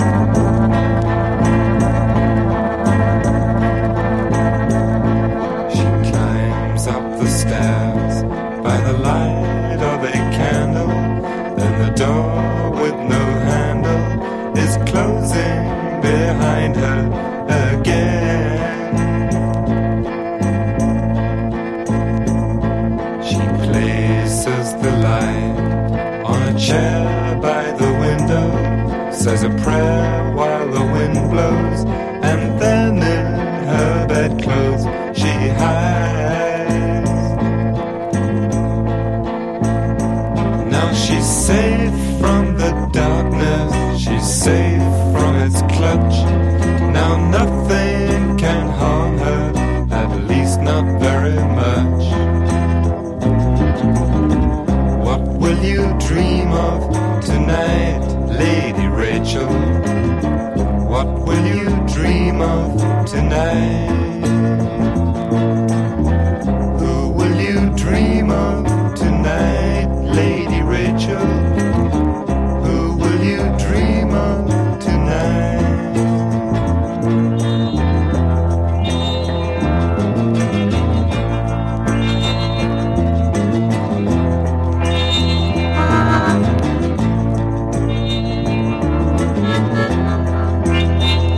マジカルなカンタベリー・ロック/屈折サイケデリック・ポップ最高峰！